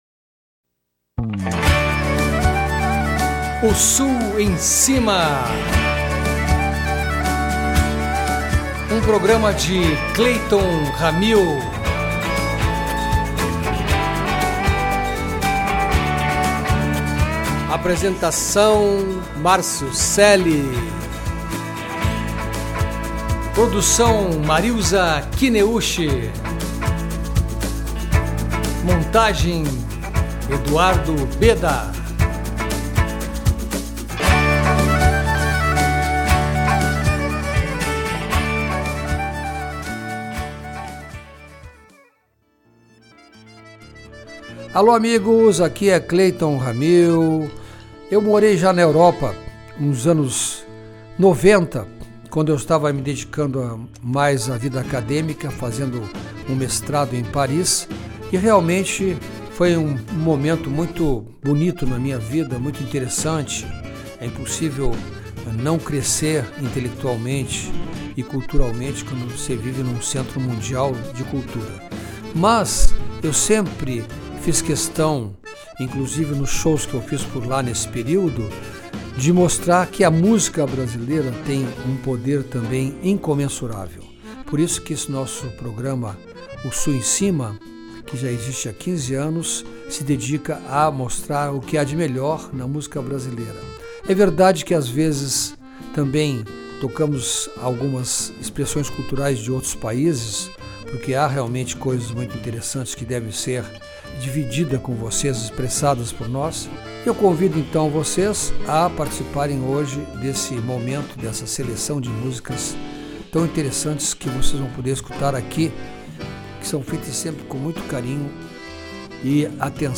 acordeon e voz
violão sete cordas
bateria